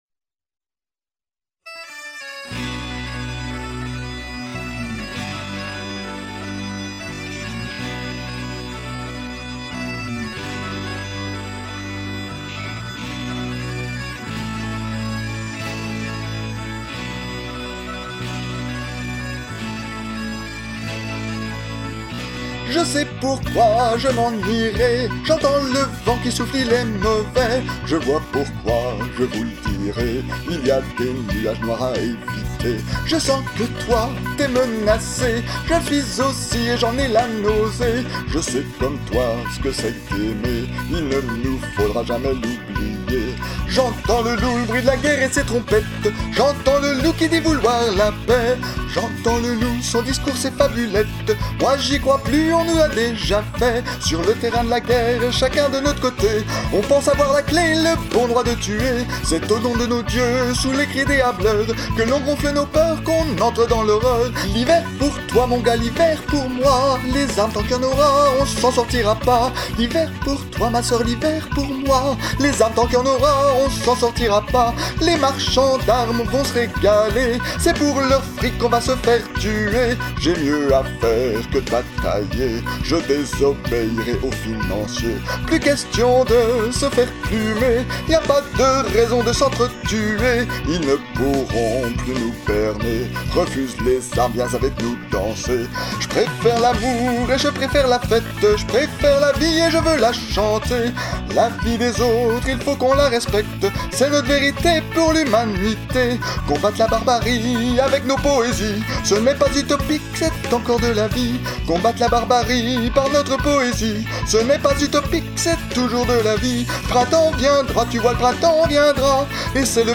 Jentends-le-loup-1-voix.mp3